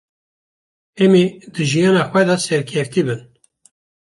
Pronunciado como (IPA)
/bɪn/